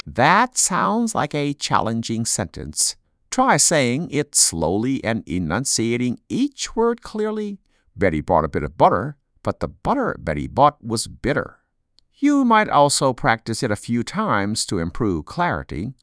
tongue_twister2_Neighbor_1.wav